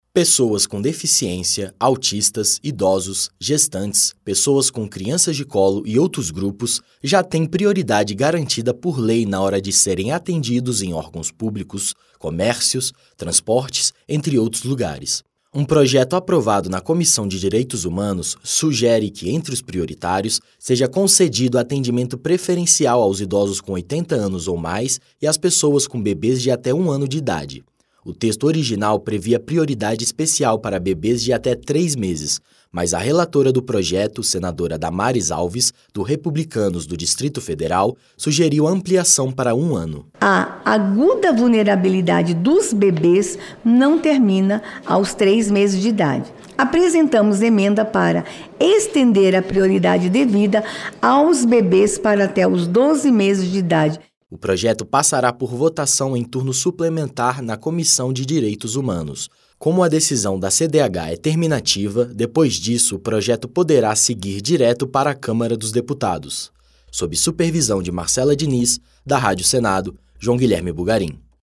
2. Notícias